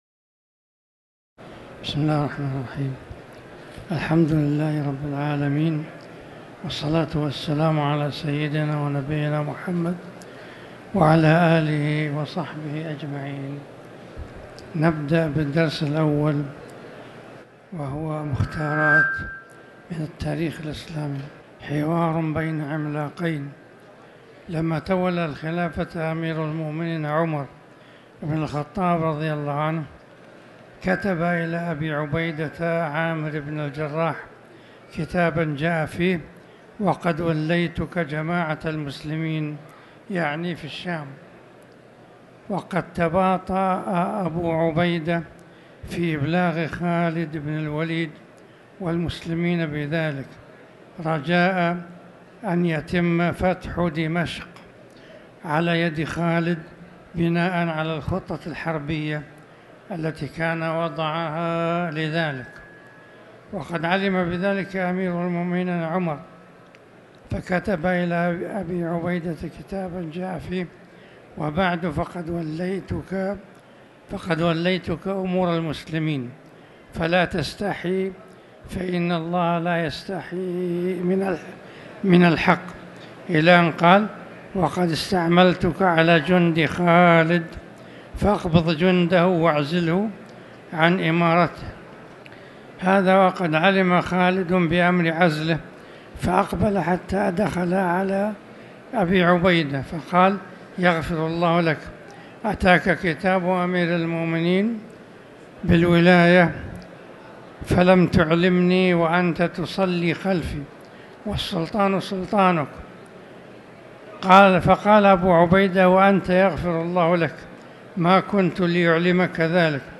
تاريخ النشر ١٨ ذو القعدة ١٤٤٠ هـ المكان: المسجد الحرام الشيخ